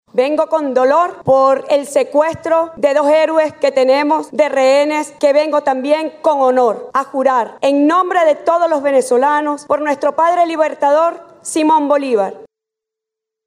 La hasta entonces vicepresidenta ejecutiva, Delcy Rodríguez, juró como presidenta encargada de Venezuela ante la Asamblea Nacional.